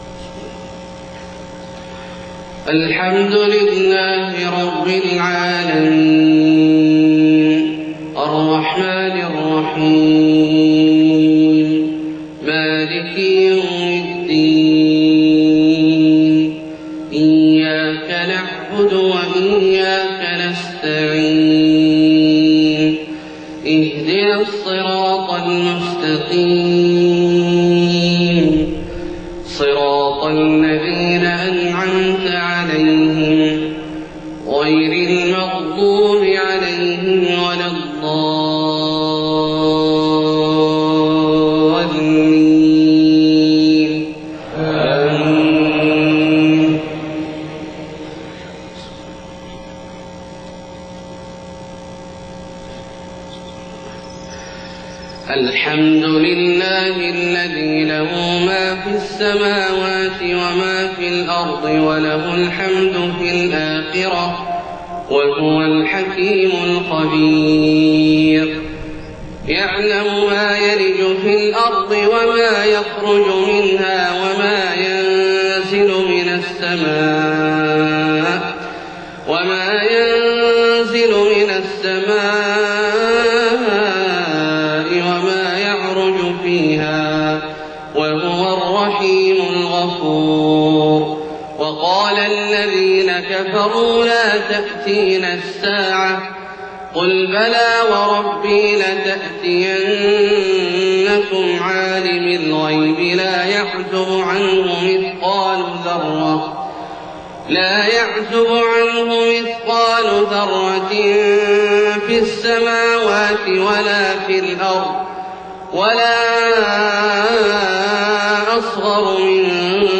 صلاة الفجر 7 صفر 1430هـ فواتح سورة سبأ 1-14 > 1430 🕋 > الفروض - تلاوات الحرمين